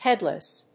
音節head･less発音記号・読み方hédləs
発音記号
• / ˈhɛdlʌs(米国英語)
• / ˈhedlʌs(英国英語)